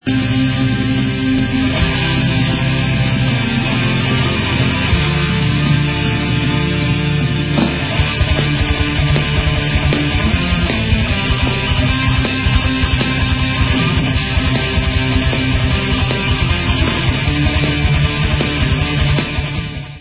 sledovat novinky v oddělení Heavy Metal